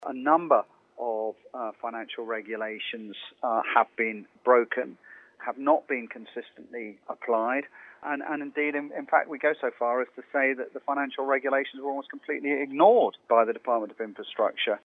Michael MHK Alf Cannan is the chairman - he says the failings are clear: